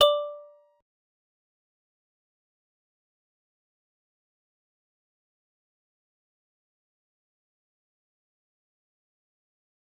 G_Musicbox-D5-pp.wav